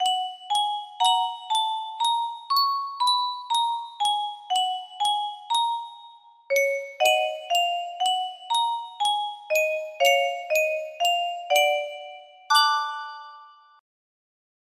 Yunsheng Music Box - JR-SH5 6671 music box melody
Full range 60